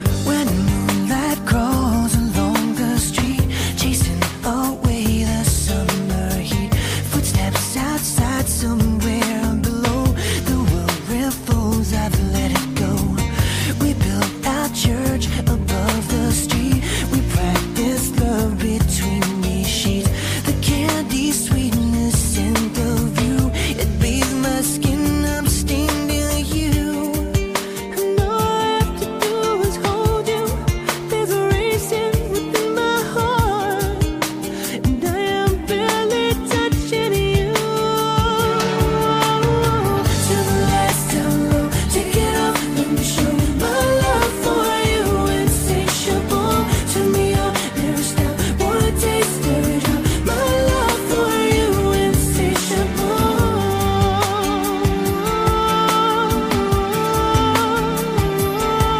наше молодость на дискотеке крутили этот медляк